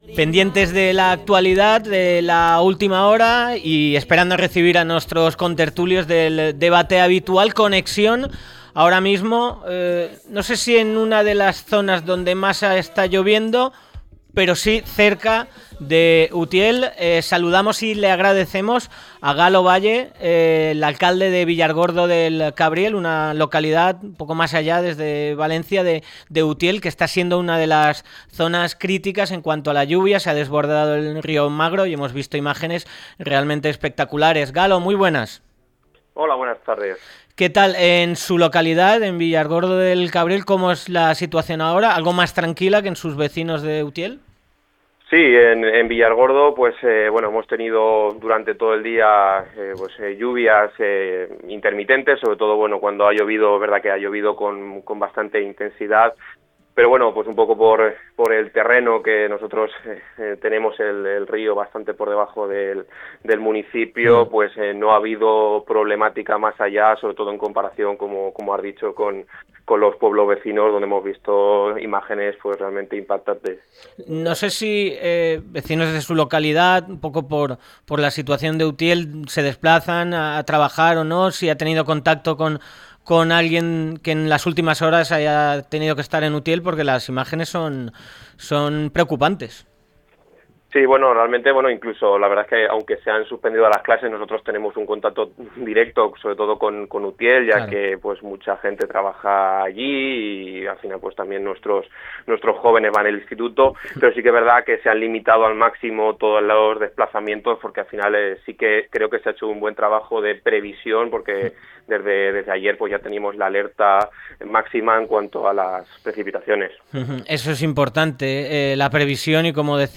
Hablamos con Galo Valle García, alcalde de Villargordo del Cabriel para hablar sobre los efectos de la DANA que ha impactado a la zona de Utiel con fuertes lluvias e inundaciones durante toda la mañana. Destacó la activación de un dispositivo de emergencias para garantizar la seguridad de los ciudadanos y la importancia de mejorar la infraestructura de drenaje para prevenir futuros desastres.